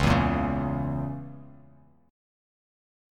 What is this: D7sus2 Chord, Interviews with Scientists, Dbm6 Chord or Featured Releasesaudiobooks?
Dbm6 Chord